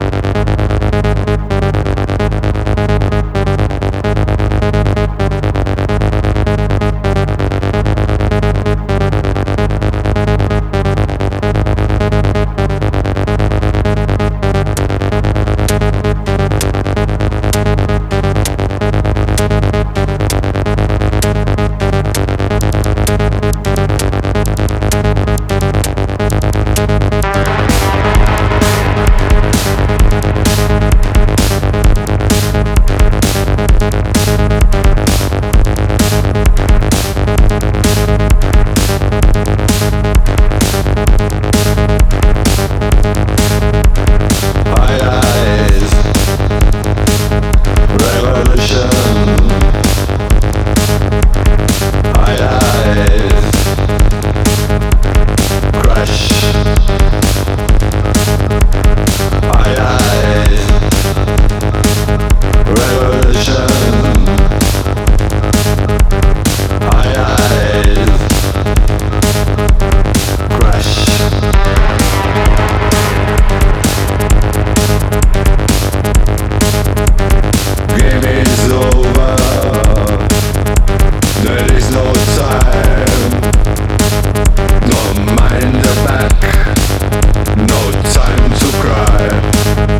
Techno EBM New Beat